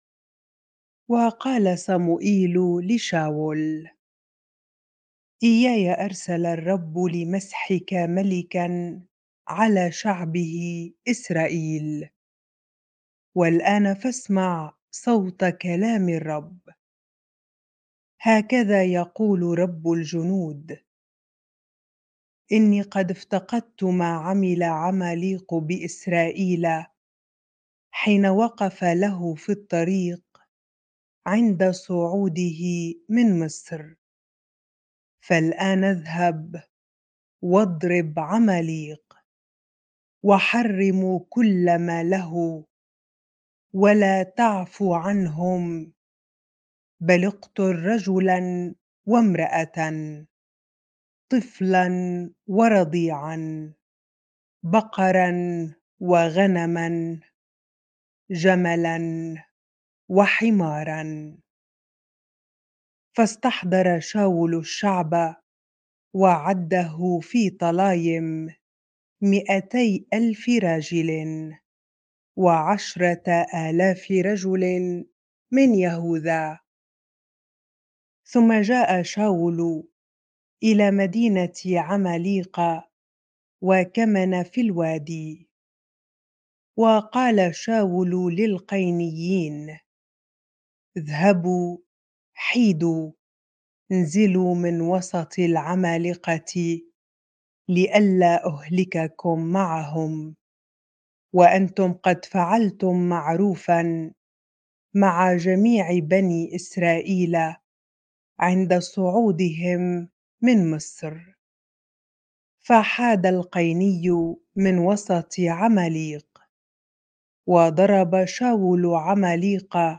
bible-reading-1Samuel 15 ar